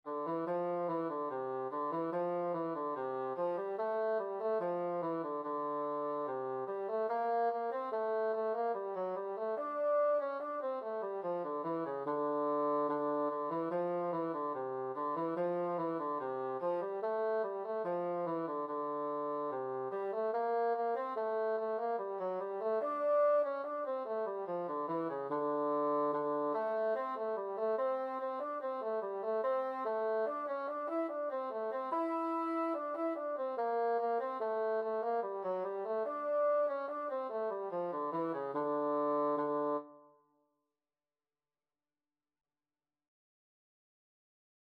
D minor (Sounding Pitch) (View more D minor Music for Bassoon )
4/4 (View more 4/4 Music)
C4-E5
Bassoon  (View more Intermediate Bassoon Music)
Traditional (View more Traditional Bassoon Music)
Irish